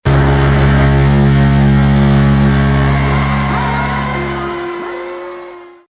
• BIG BOAT FOGHORN.mp3
A big ship foghorn blaring, moving towards new seas.
big_boat_foghorn_qfo.wav